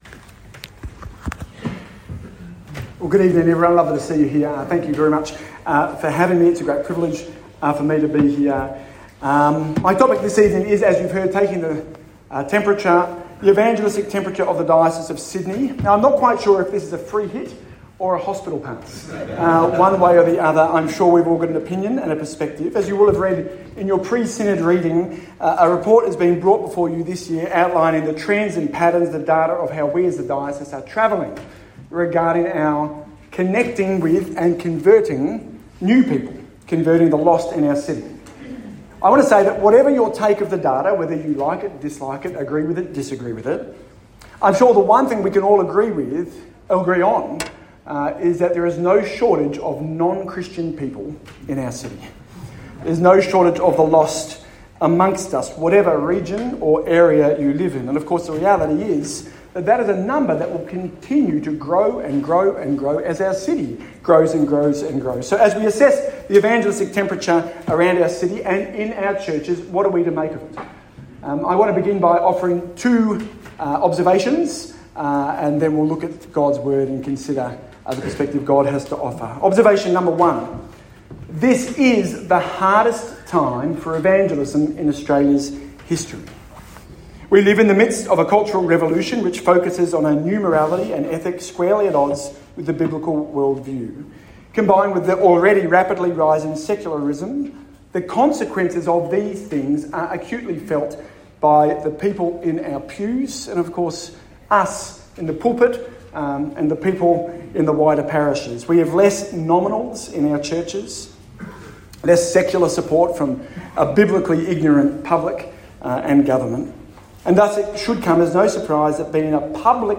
A “Must Hear” address
at the ACL Synod Dinner 2024